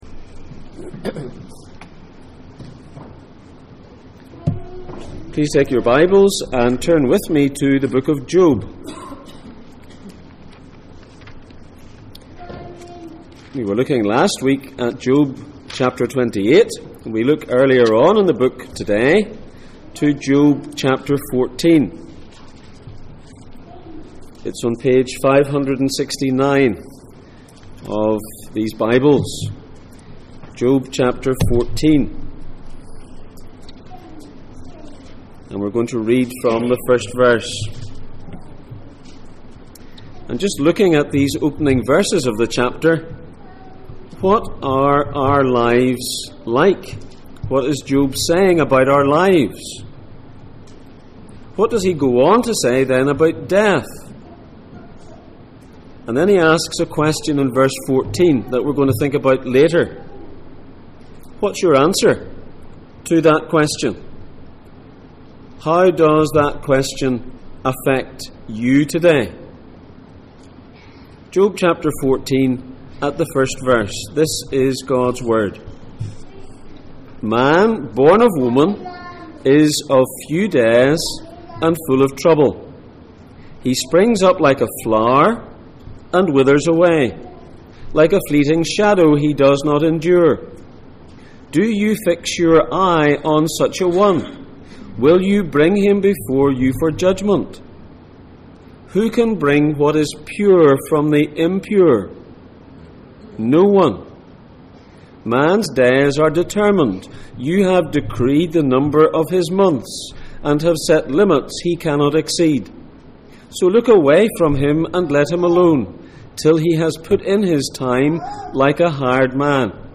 Questions from Job Passage: Job 14:1-14, Hebrews 9:27 Service Type: Sunday Morning %todo_render% « Where can wisdom be found?